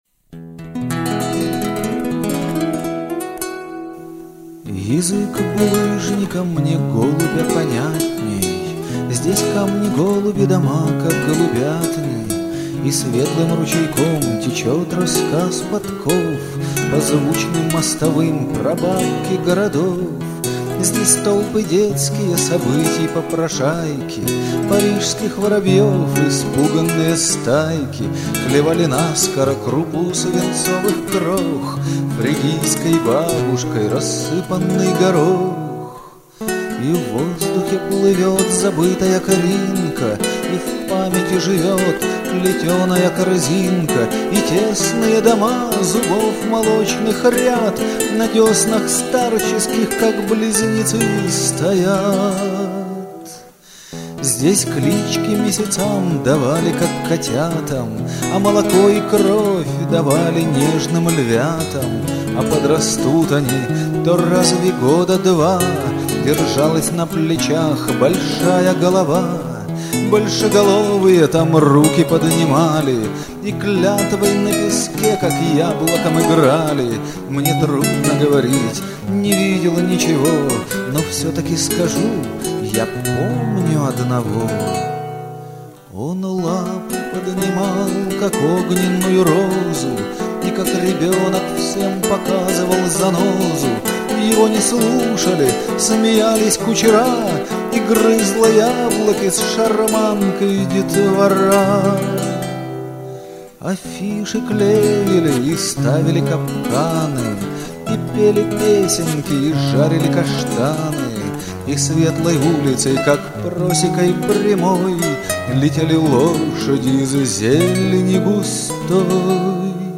Песни на стихотворение: